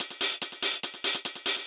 K-6 Hi Hats.wav